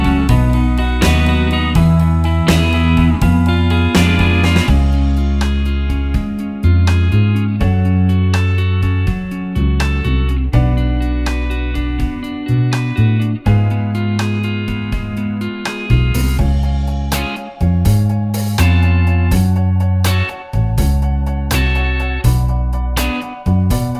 no Backing Vocals Rock 'n' Roll 3:30 Buy £1.50